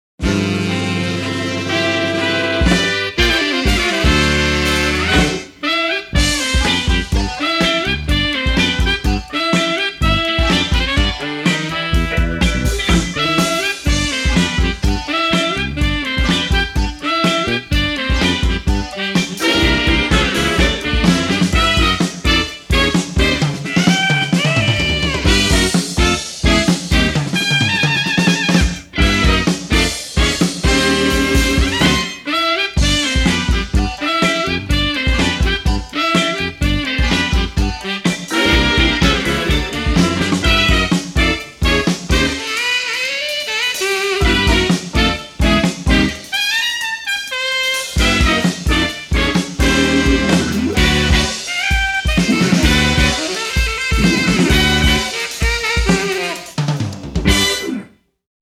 Show band with horns.